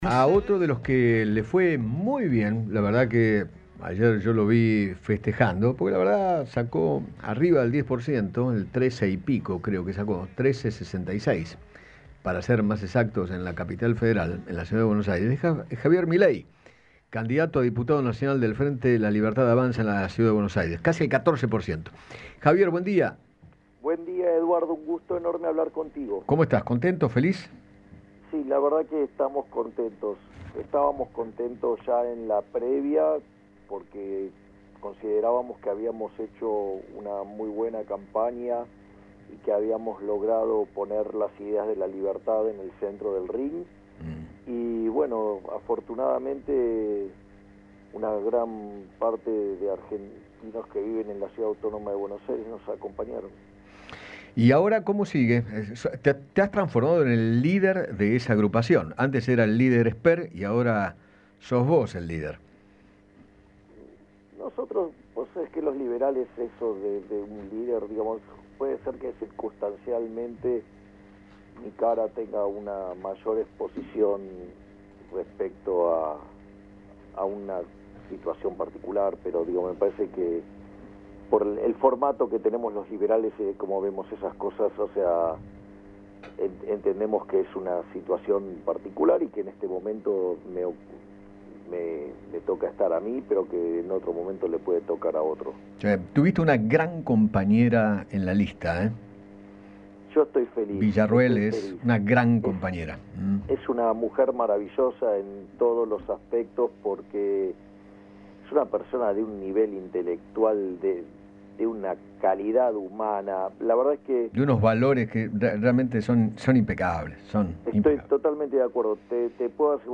Javier Milei, candidato a diputado nacional, conversó con Eduardo Feinmann sobre la gran sorpresa que dio en las PASO y expresó que han “logrado armar una buena mezcla”.